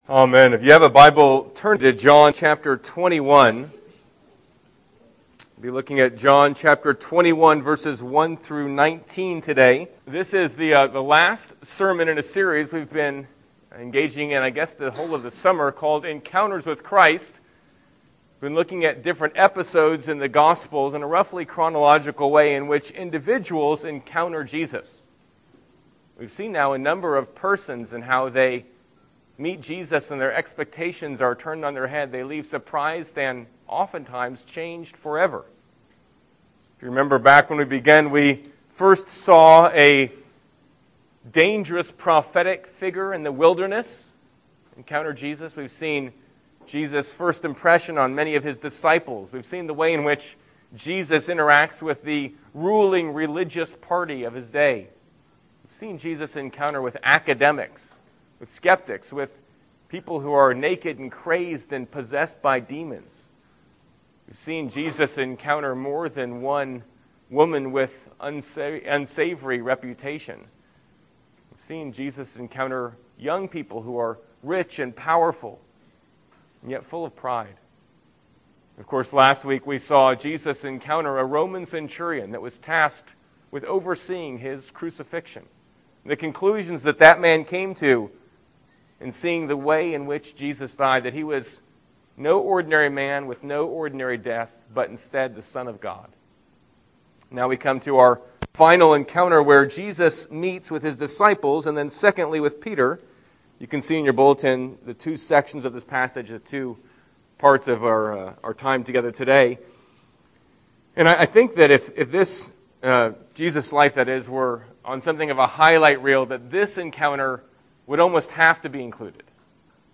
Sermons - Encounters with Jesus < King of Kings, PCA